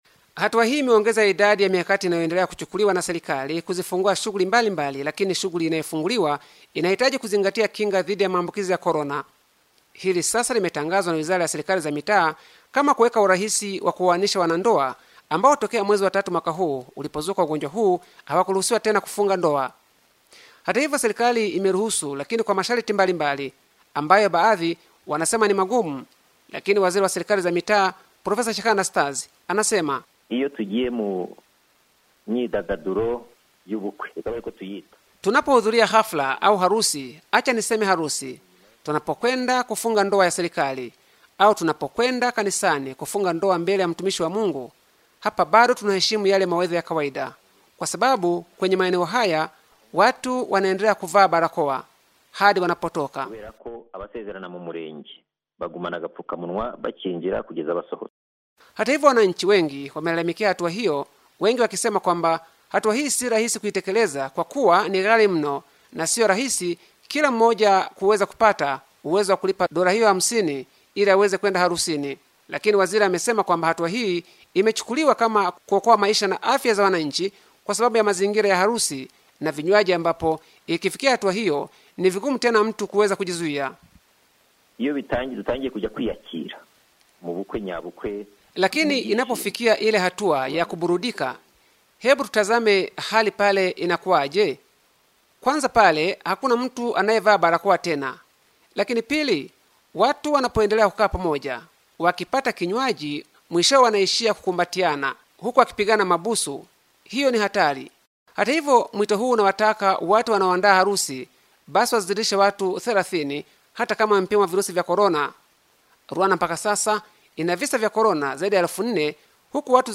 Hata hivyo gharaza upimaji zitakuwa dola 50 kwa kila mtu mmoja kitu ambacho wananchi wamekilalamikia. Kutoka Kigali